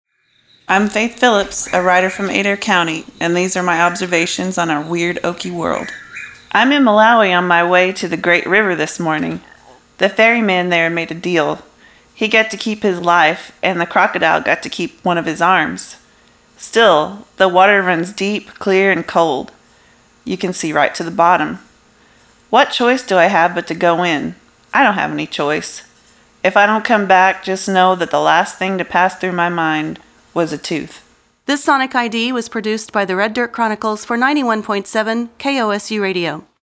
In the middle of our recording session, a whippoorwill got so close we captured its call and added those haunting sounds to her introduction.
All told, there are about thirty recorded musings and we look forward to hearing them on public radio very soon…or perhaps here too.